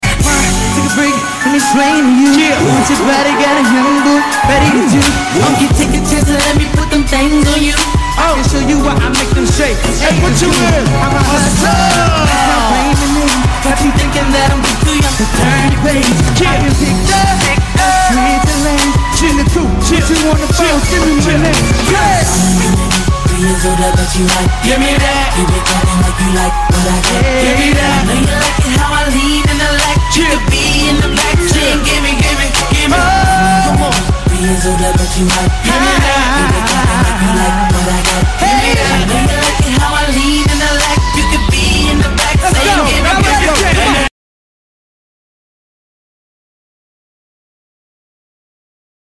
BET Awards 2006 PART 3